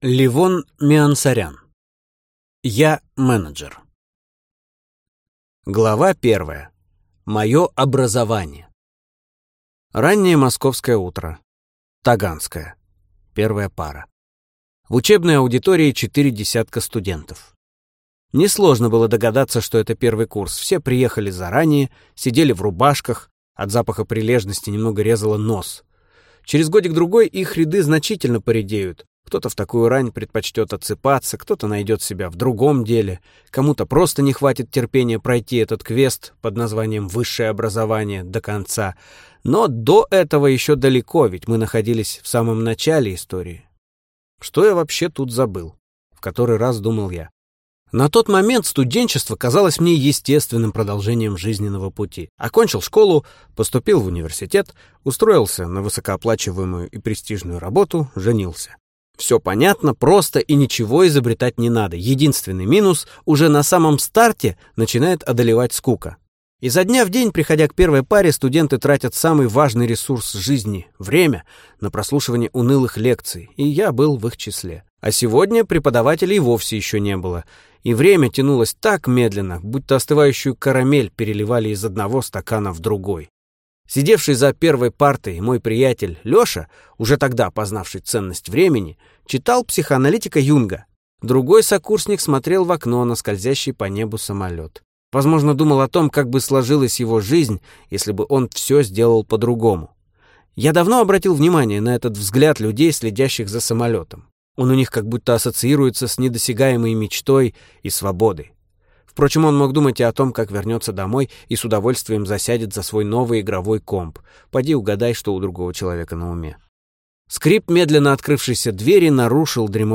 Аудиокнига Я – менеджер | Библиотека аудиокниг